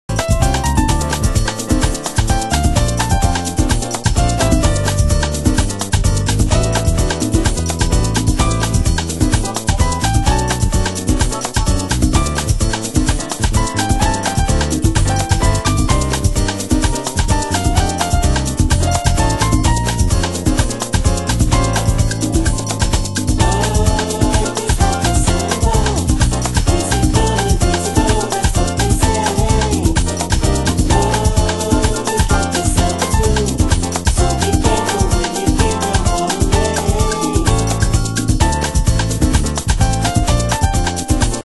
爽快なボッサトラックに乗り、鳴りの良いKEYが耳に残る人気トラック！